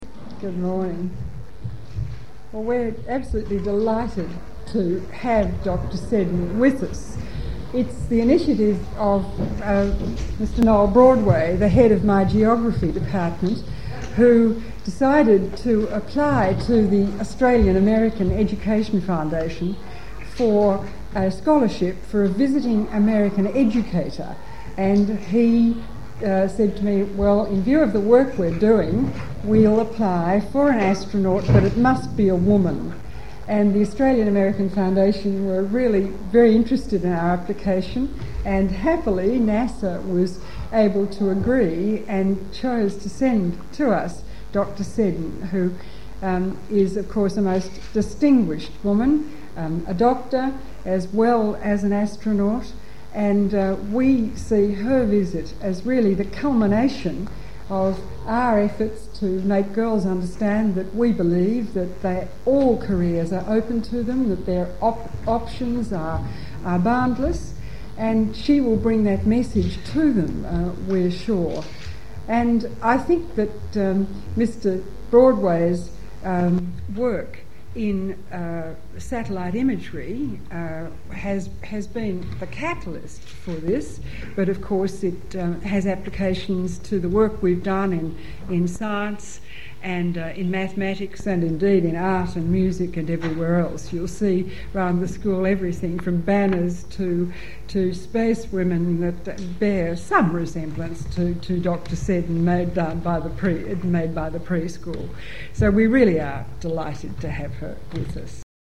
Melbourne   Introduction of visiting NASA astronaut Rhea Seddon. Recorded at Press Conference, Toorak.